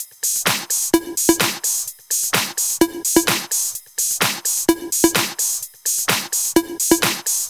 VFH1 128BPM Moonpatrol Kit 3.wav